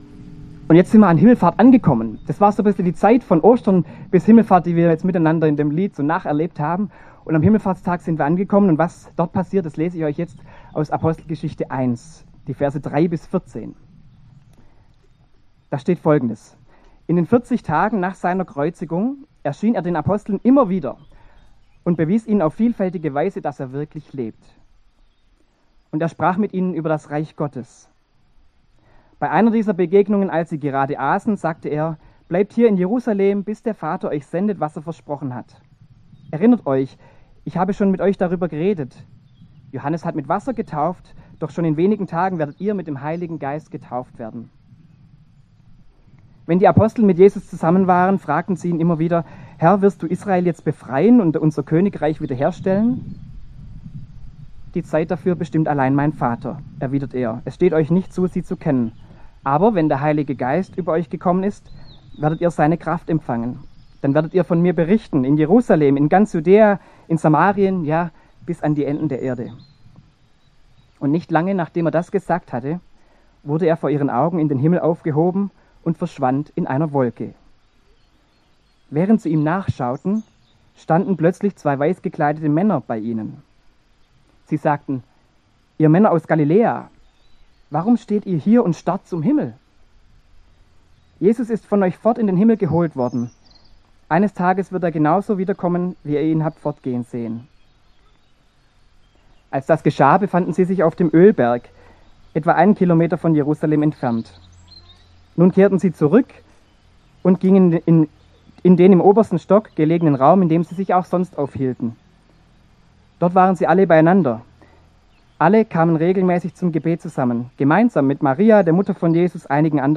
Predigt an Himmelfahrt
im Gottesdienst im Grünen